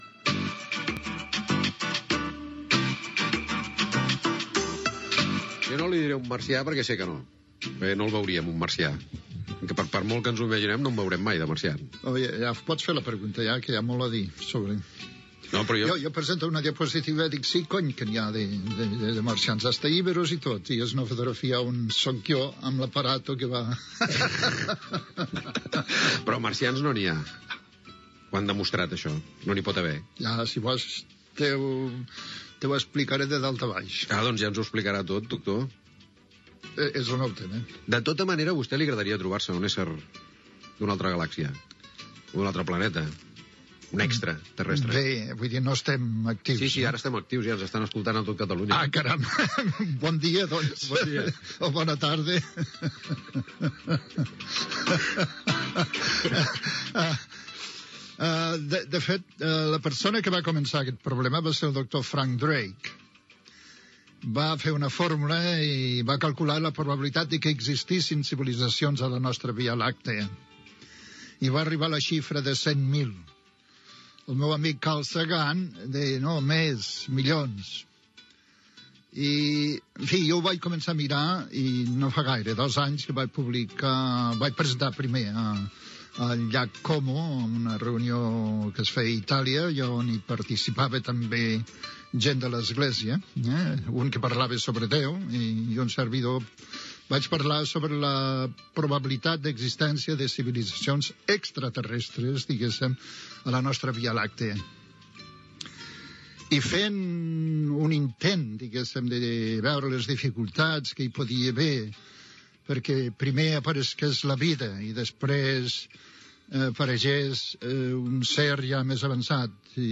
Primeres preguntes de l'entrevista al científic Joan Oró sobre l'espai i les possibilitats que hi hagi vida fora de la Terra, indicatiu del programa, continuació de l'entrevista.
Divulgació